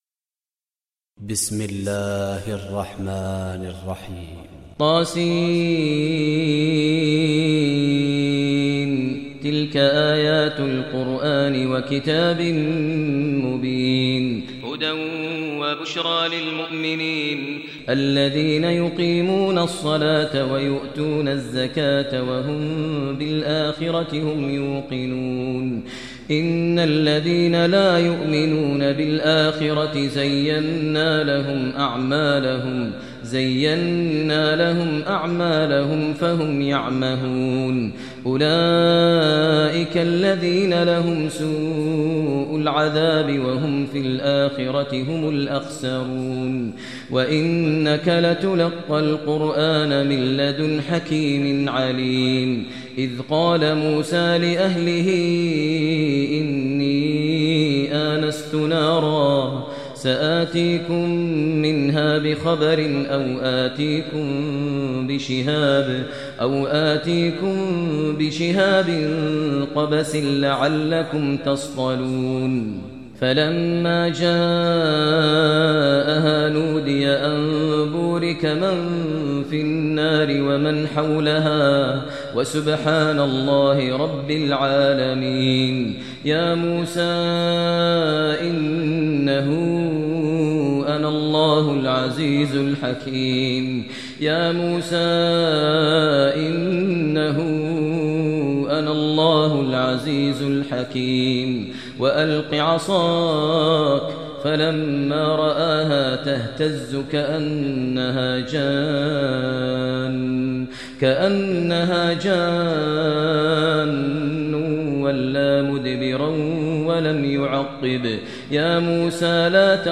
Surah Naml Recitation by Maher al Mueaqly
Surah Naml, listen online mp3 tilawat / recitation in Arabic recited by Imam e Kaaba Sheikh Maher al Mueaqly.